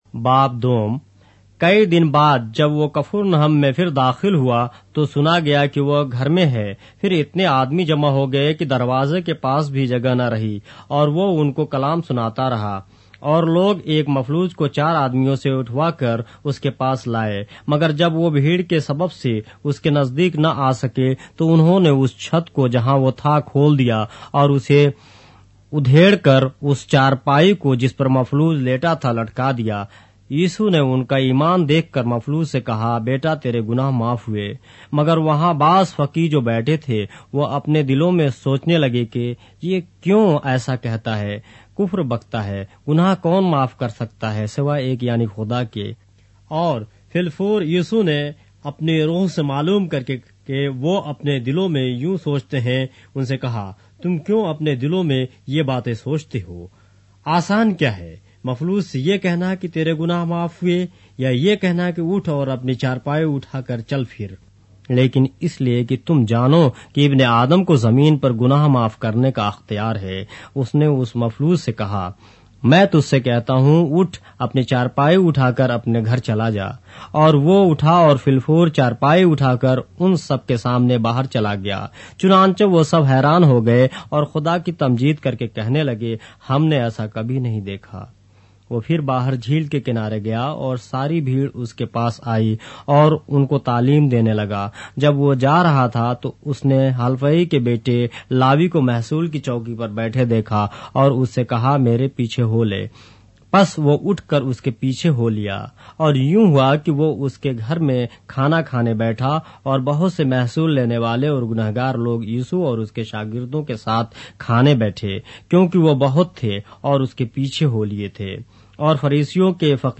اردو بائبل کے باب - آڈیو روایت کے ساتھ - Mark, chapter 2 of the Holy Bible in Urdu